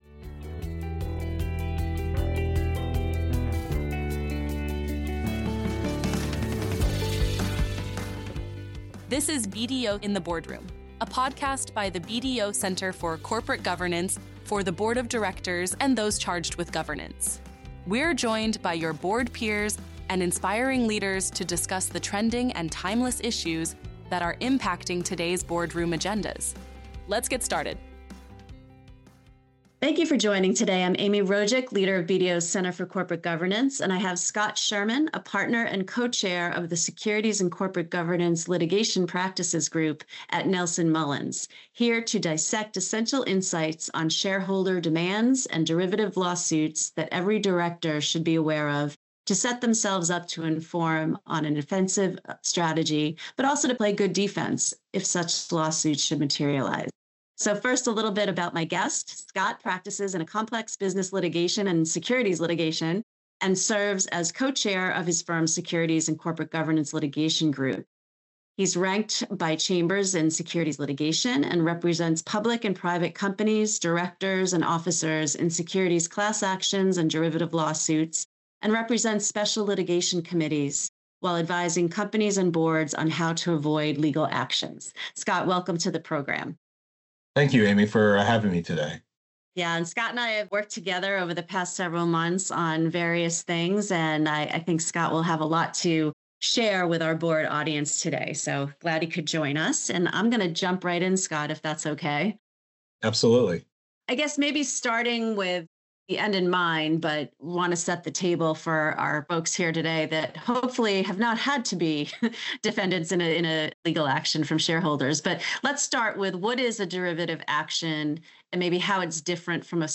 Each episode features a topical discussion with board peers and subject matter experts on both trending and timeless boardroom issues mitigating risk in an increasingly digital world, navigating your board career, financial and ESG reporting, shareholder activism and more.